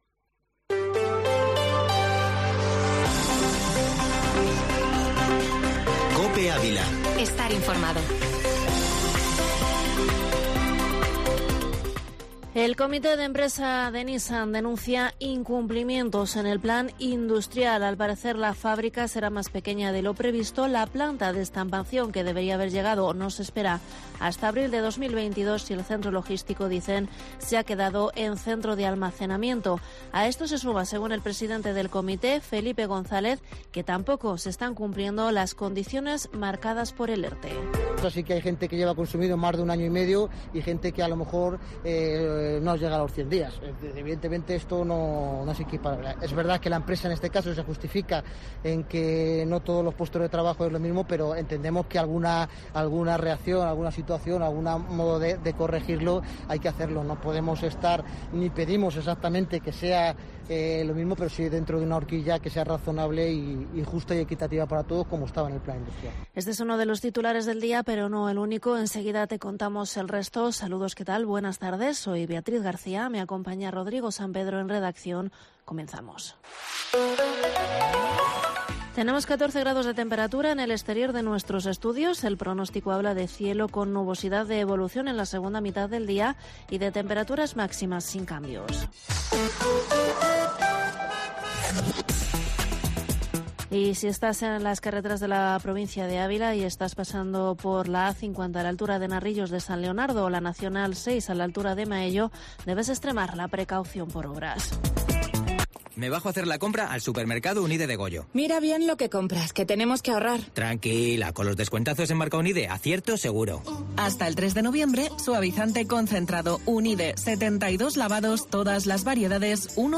Informativo Mediodía COPE en Ávila 29/10/21